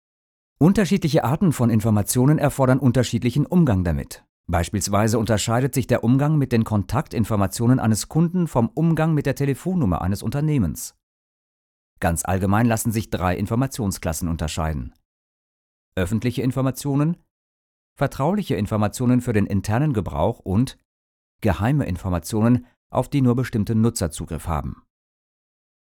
German male versatile professional voice over talent and actor - Fluent in French
Kein Dialekt
Sprechprobe: eLearning (Muttersprache):